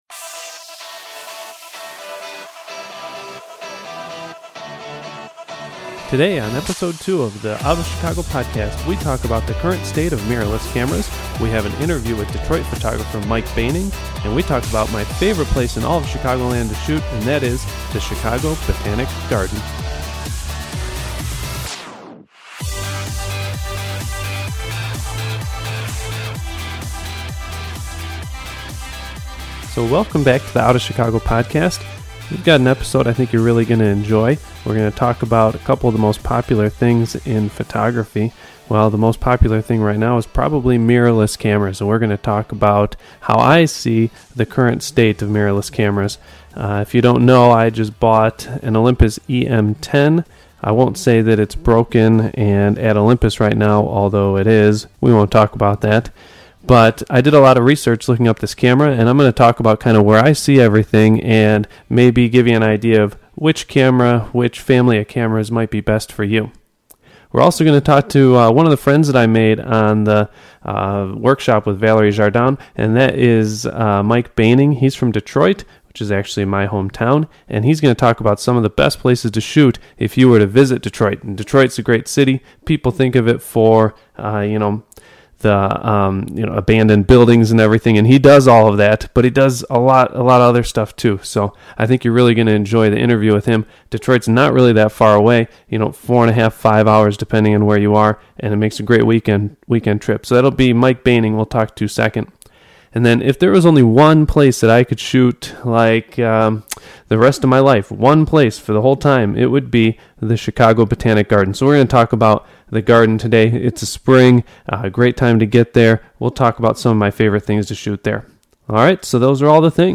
An interview […]